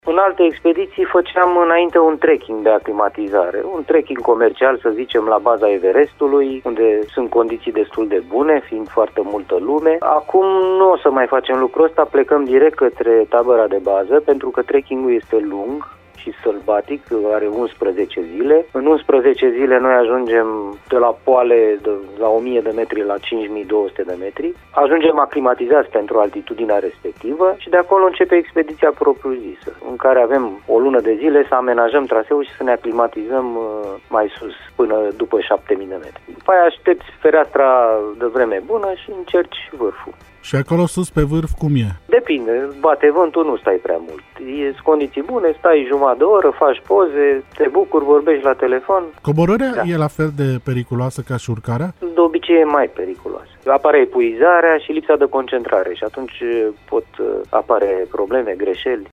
Invitat vineri, 11 februarie, în direct la Radio Cluj,